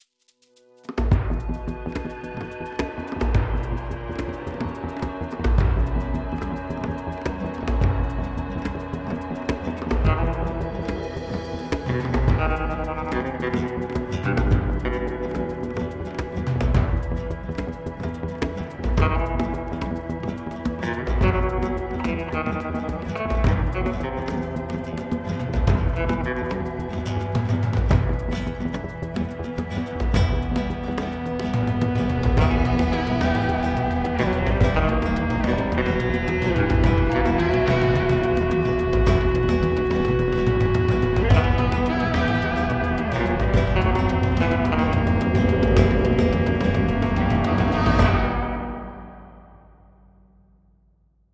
Obra Instrumental Trilha Sonora